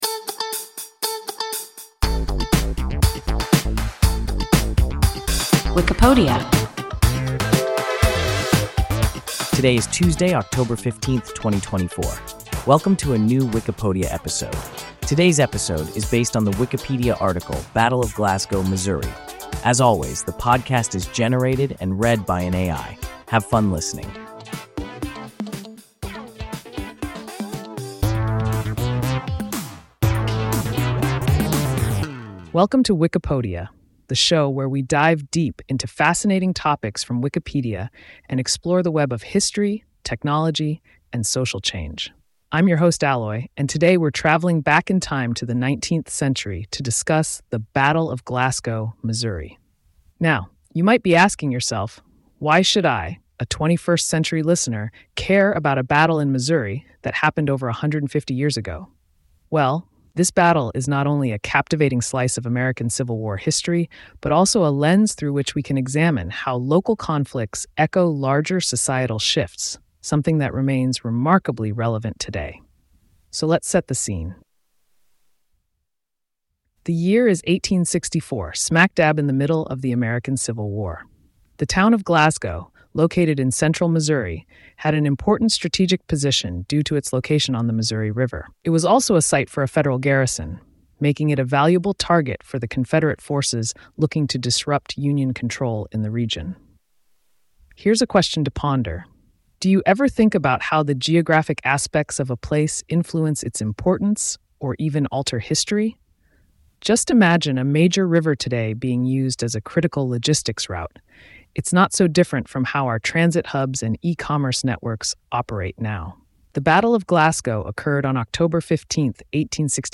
Battle of Glasgow, Missouri – WIKIPODIA – ein KI Podcast